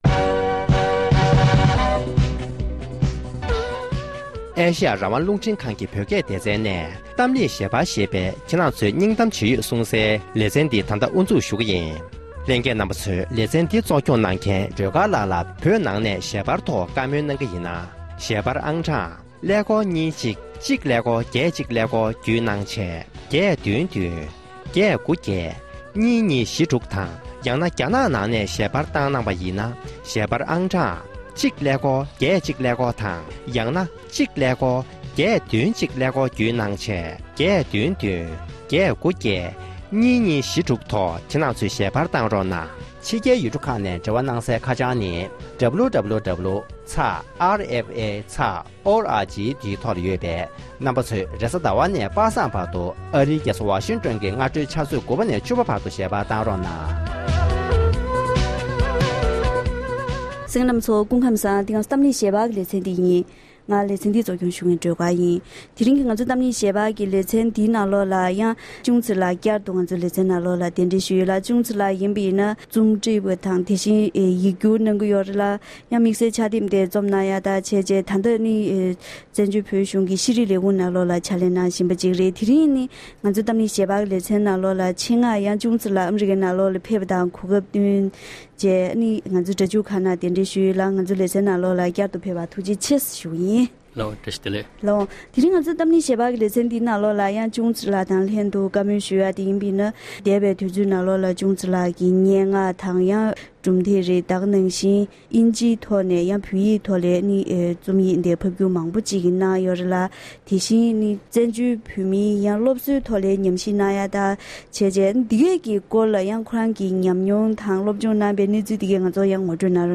བཀའ་མོལ་ཞུས་པ།